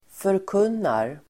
Uttal: [förk'un:ar]